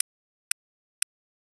The virtual source is located at -90° (right hand).
Burst_S-90d_ref+o2.wav